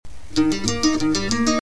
Requinto4